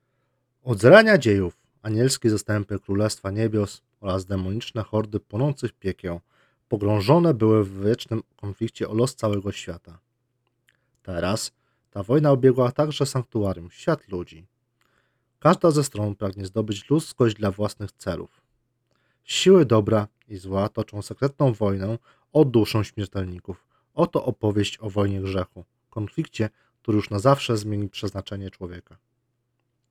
Oba nagrania są bez obróbki, dzięki czemu sami będziecie mogli ocenić jakość recenzowanego modelu.
Próbka audio bez obróbki – Silver Monkey X Noberu